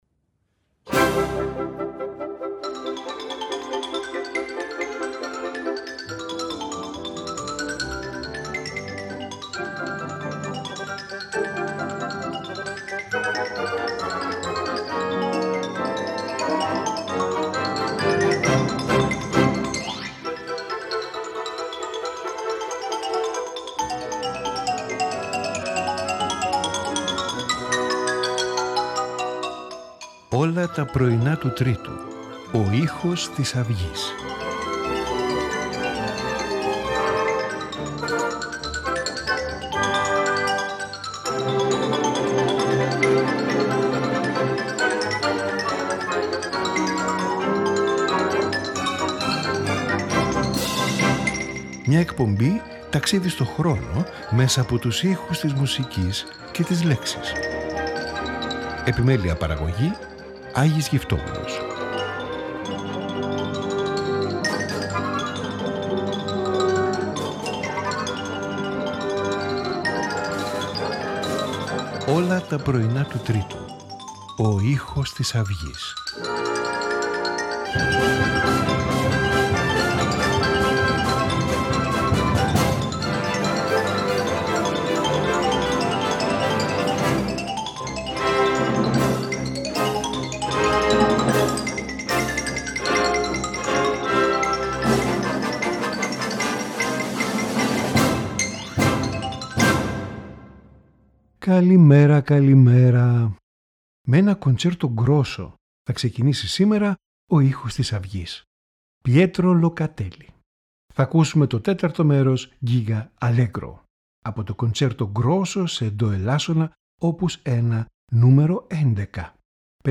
Concerto Grosso
Violin Sonata
Piano Trio
Symphony